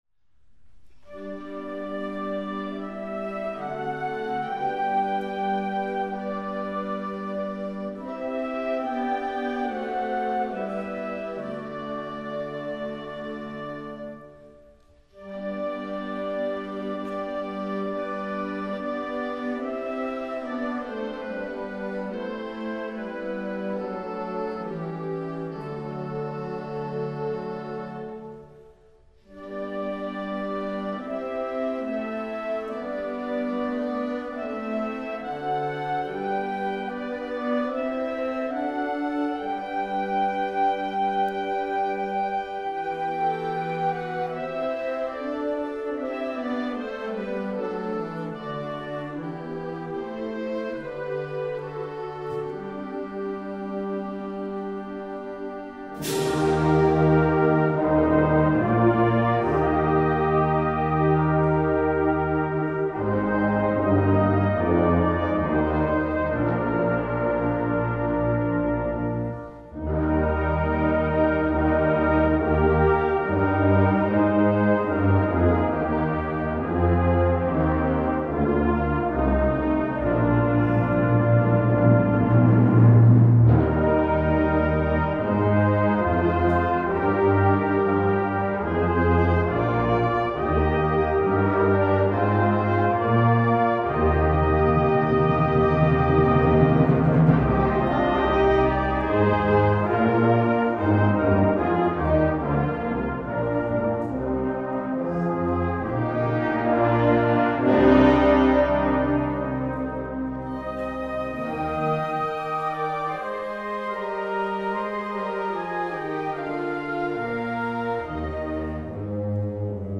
February 25 Concert Band Recordings